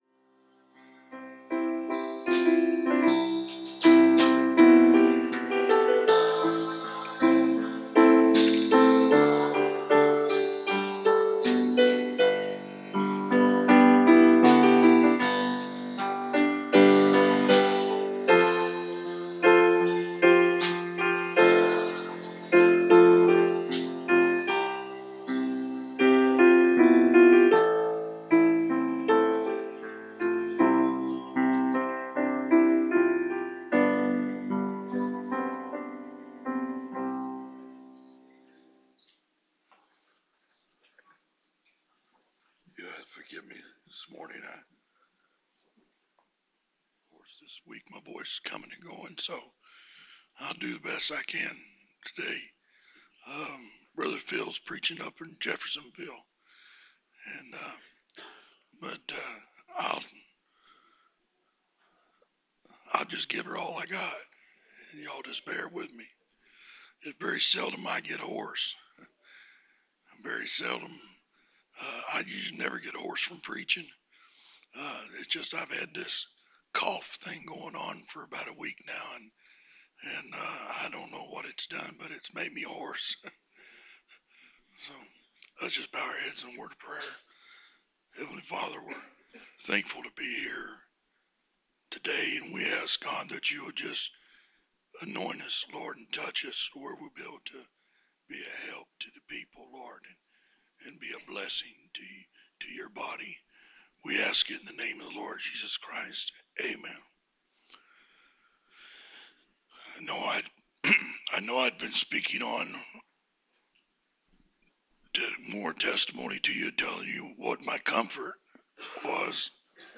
The sermon scripture list – Job 9:28-35 – Psalms 51:12-13 – Hebrews 4:12-16 – 1 John 2:1-2 – Romans 8:37 – Psalms 119:116 – Isaiah 41:10 – Isaiah 42:1 – Revelation 1:18 – Hebrews 2:14-18